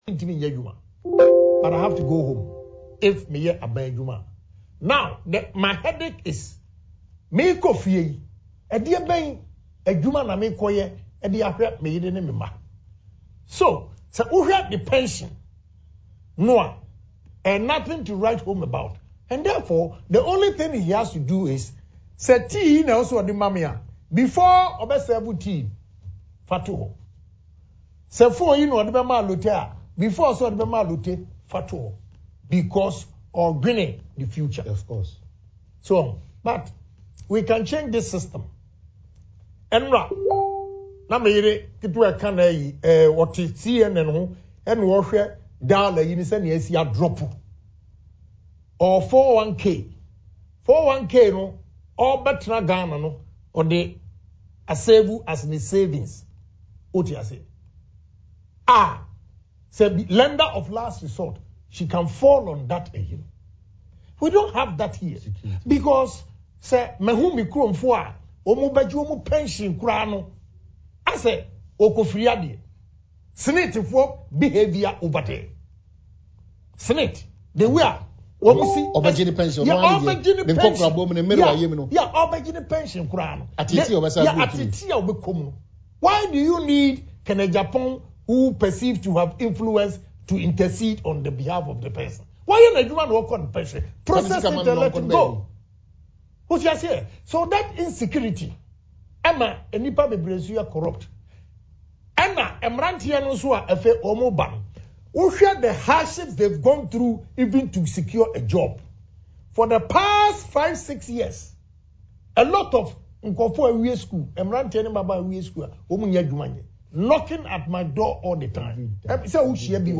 Speaking on Adom TV’s Morning Show “Badwam” Tuesday, the outspoken MP indicated that all these treatments and more breeds corruption in the country.